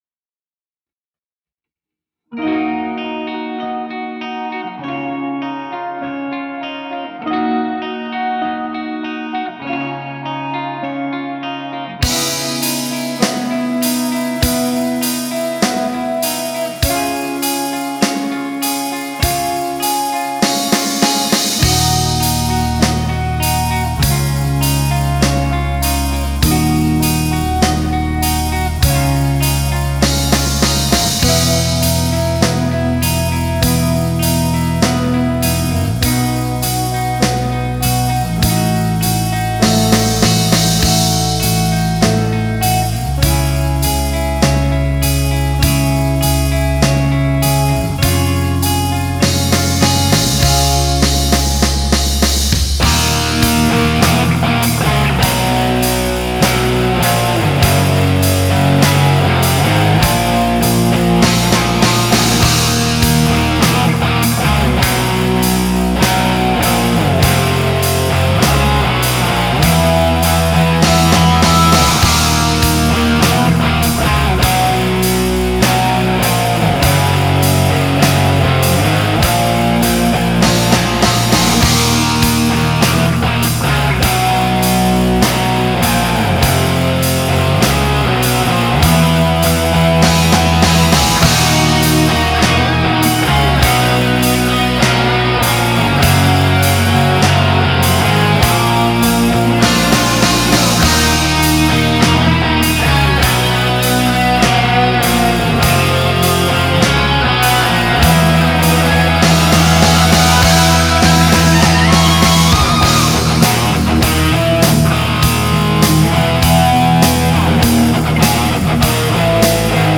Melodic Rock 101
Tuning = Eb, Ab, Db, Gb, Bb, Eb,
Song Intro - Aeolian & Locrian modes used
This blending makes for a very cool effect,